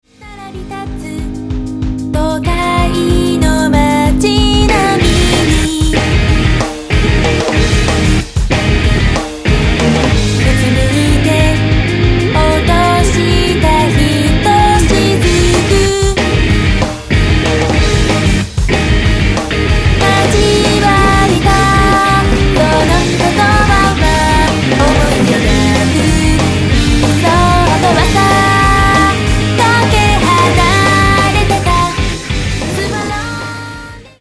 音源は全てマスタリング前のものとなります。